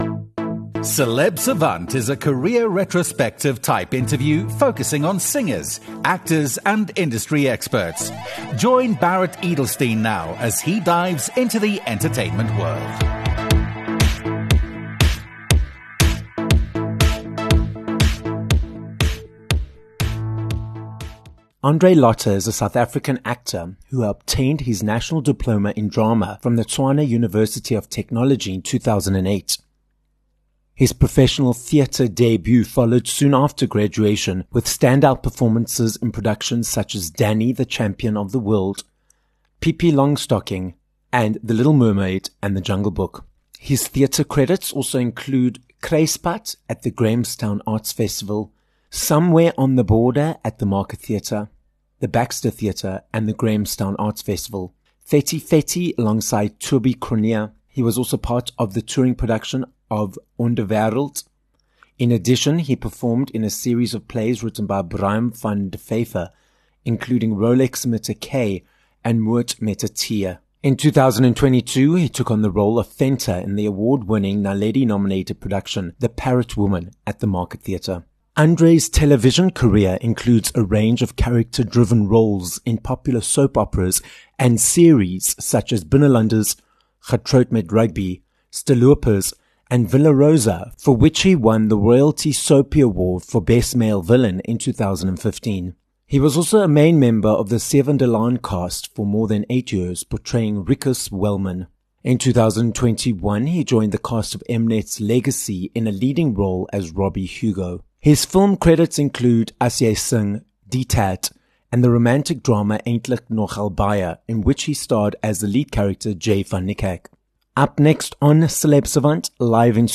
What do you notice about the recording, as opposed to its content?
He discusses the concept of research when it comes to acting, we unpack performing on stage, in front of the camera, and dive into the world of soap operas. This episode of Celeb Savant was recorded live in studio at Solid Gold Podcasts in Johannesburg, South Africa.